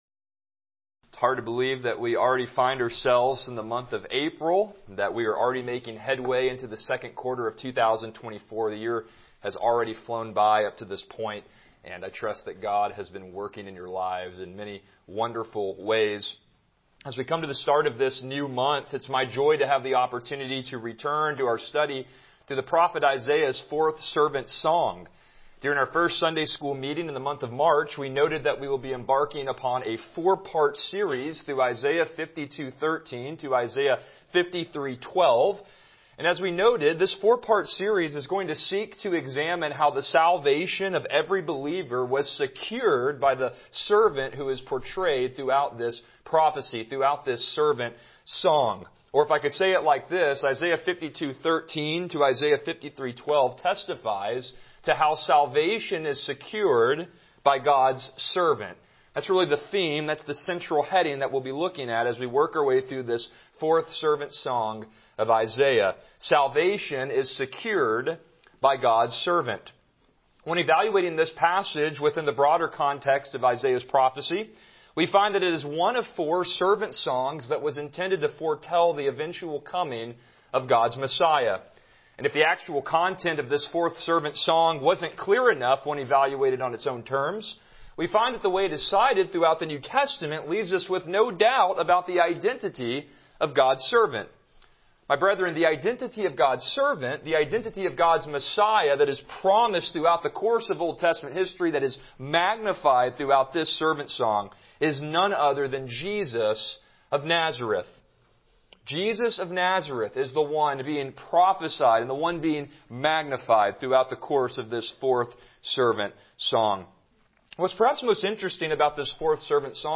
Passage: Isaiah 53:4-9 Service Type: Sunday School